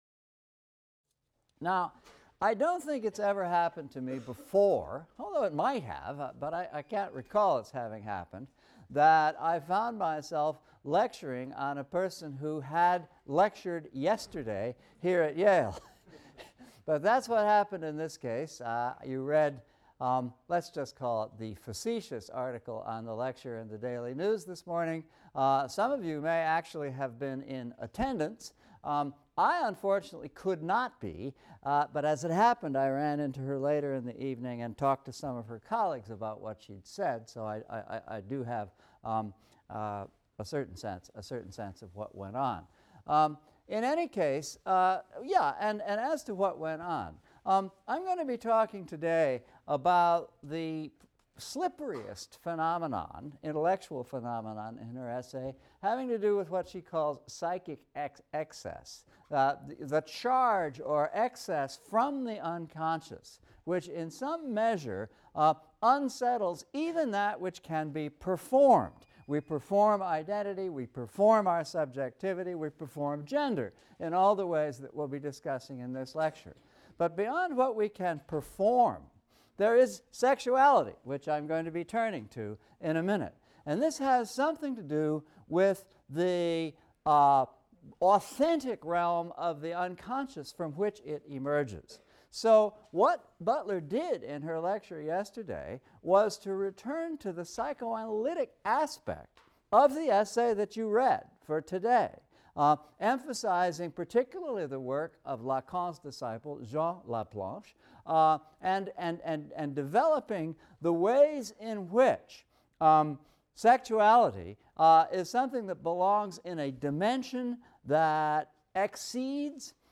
ENGL 300 - Lecture 23 - Queer Theory and Gender Performativity | Open Yale Courses